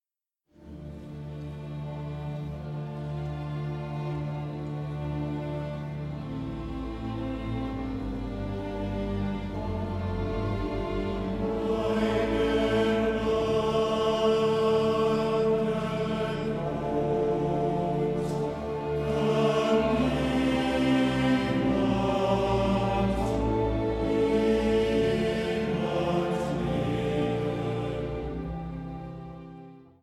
• kurzweilige Zusammenstellung verschiedener Live-Aufnahmen
Chor, Orchester, Orgel